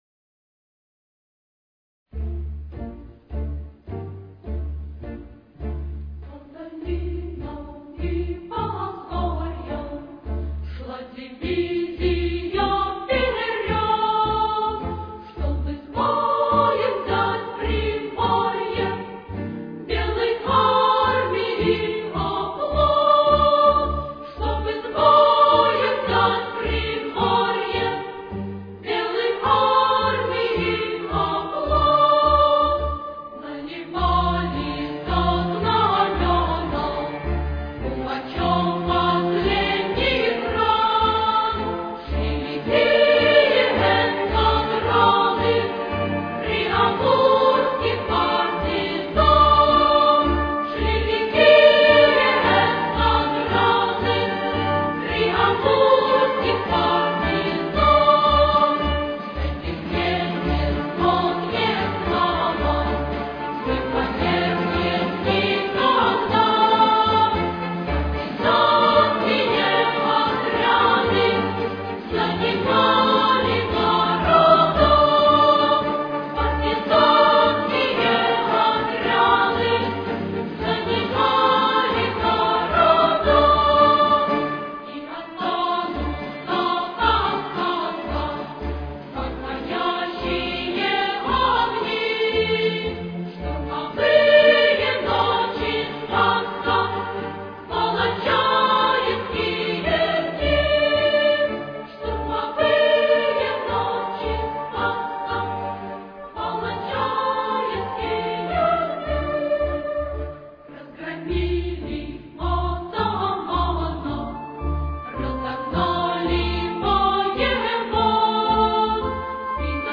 Ре минор. Темп: 113.